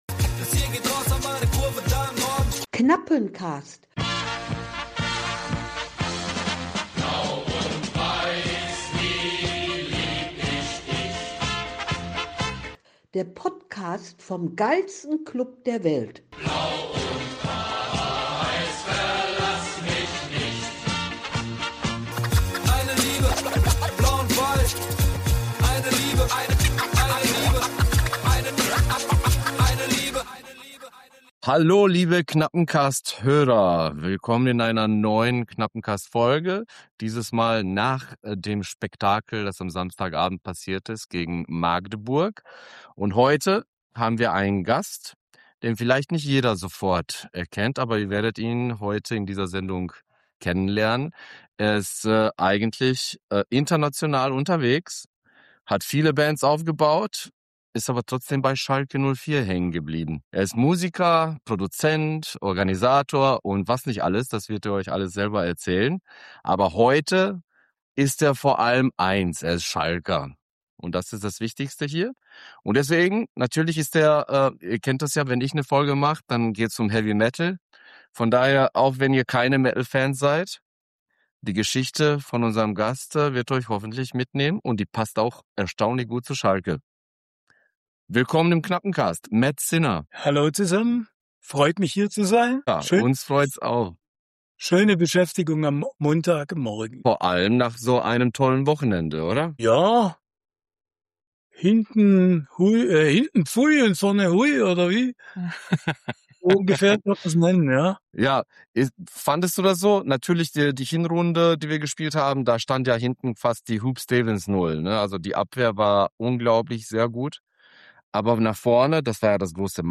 Er teilt seine faszinierende Reise durch die Musikbranche, seine Verbindung zu Schalke 04 und die Parallelen zwischen sportlichem, gesundheitlichem und musikalischem Neuanfang. In diesem Interview teilt Mat, Musiker und Schalke-Fan, seine faszinierenden Erfahrungen von der Jugend bis zur Profi-Karriere, seine Leidenschaft für Schalke, seine Meinung zu aktuellen Fußballthemen und Einblicke in sein Musikerleben.